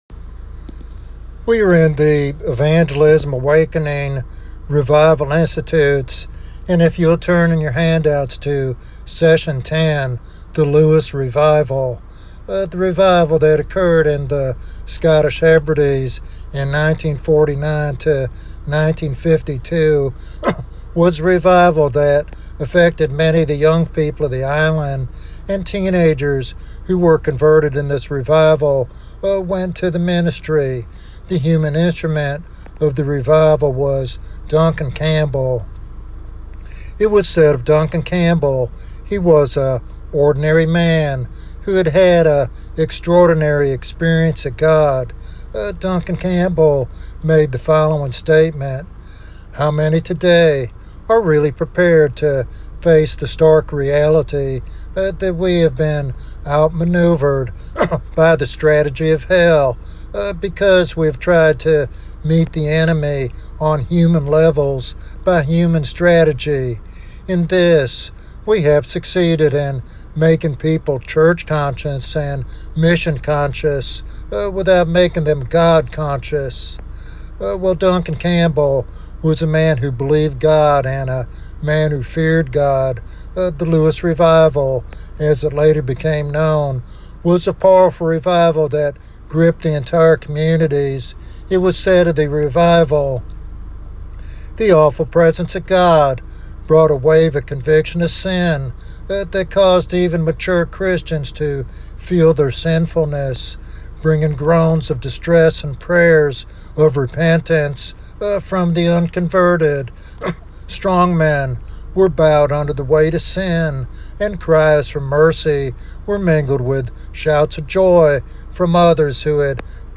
This sermon challenges listeners to pursue personal holiness and to engage deeply in prayer as the foundation for revival in their own communities.